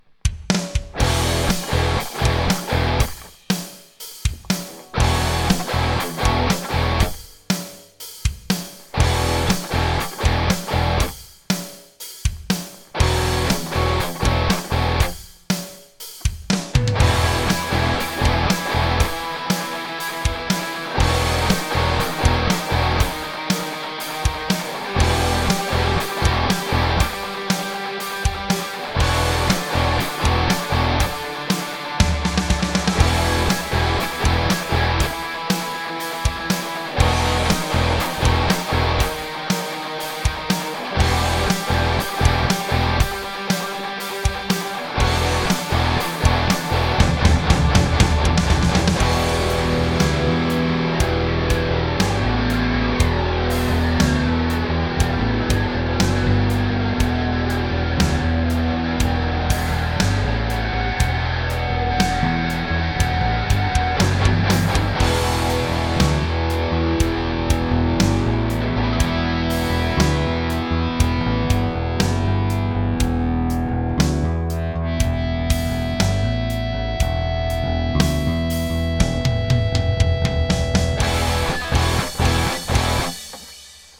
Okay, here's some clips miking my entryway. First thing I did was roll a cab out there and laid it down so it was pointing up into the universe. Then I ran upstairs and hung some SDCs over the upstairs railing.
The up one was with the idea of catching ceiling reflections, the down one was, well, down towards the cab.
And just about everything in the house rattled under the crushing weight of cranked Plexi.